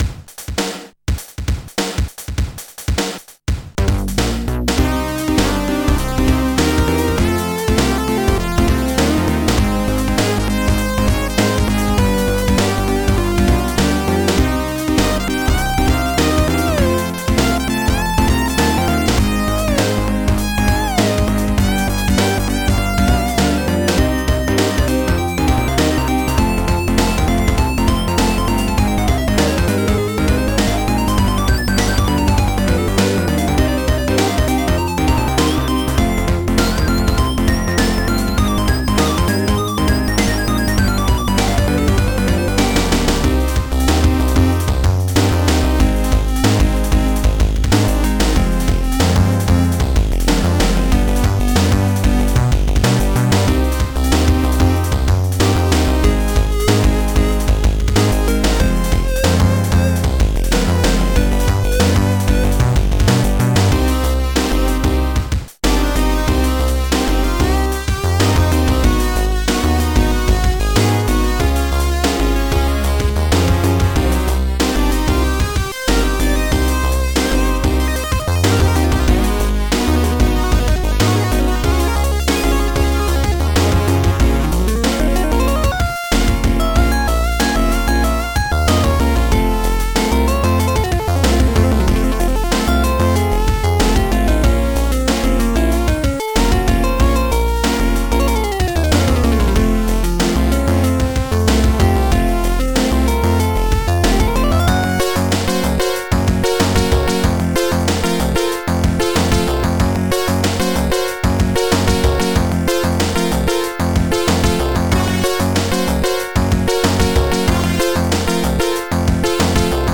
An atmospheric, uptempo, Psytrance-style synthesizer tune.